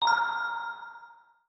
Airy Echo Metallic Alert.wav